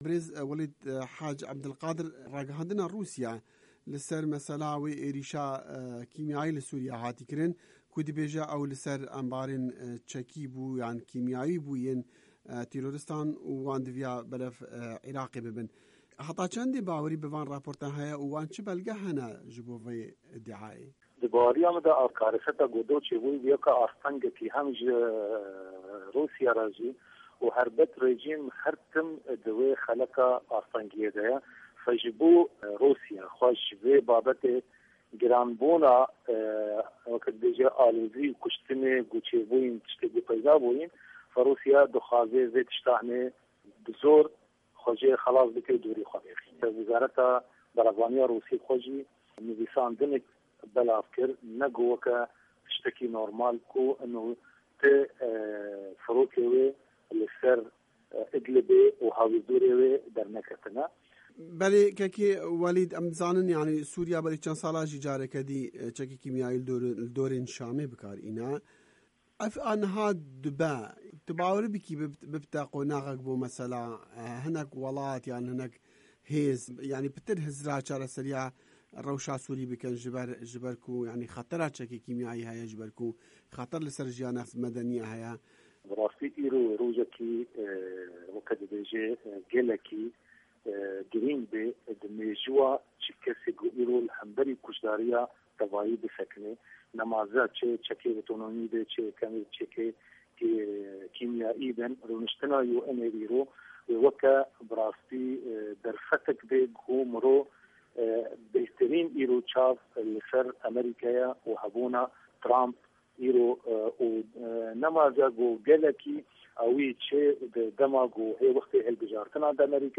Di hevpeyvînekê de digel VOA